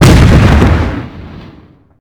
grenadeexplodenextroom.ogg